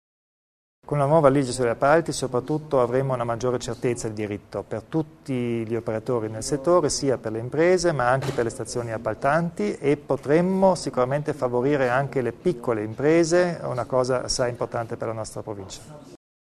Il Presidente Kompatscher spiega le novità in tema di appalti